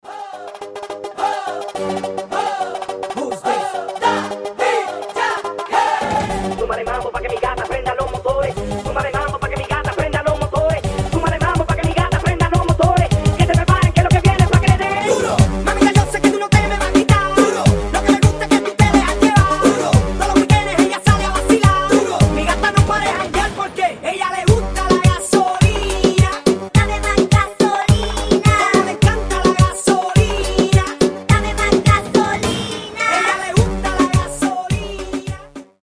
Танцевальные рингтоны
Ритмичные , Быстрые , Нарастающие
Реггетон